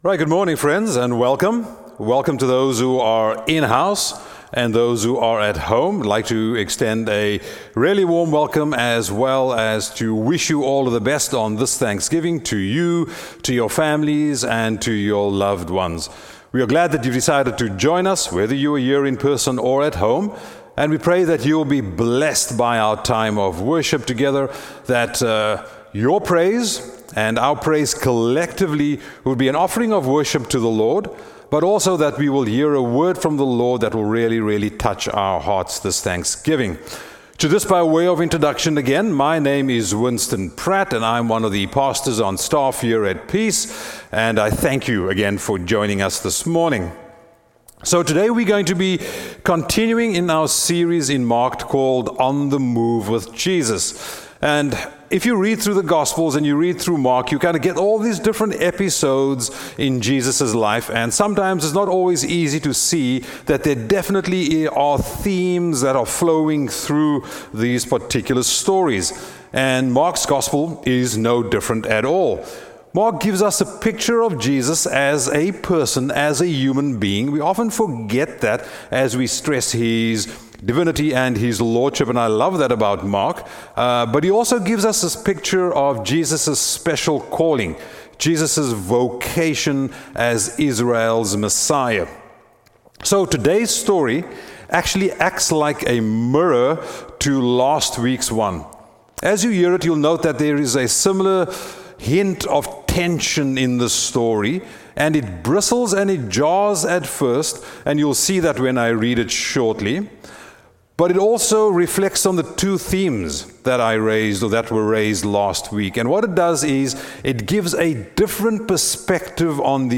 Sermons | Peace Mennonite Church